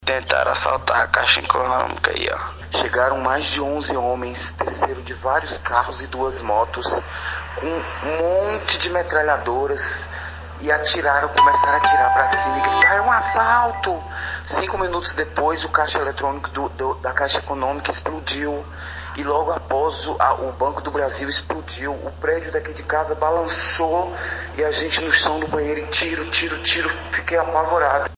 Ouçam relatos dos moradores: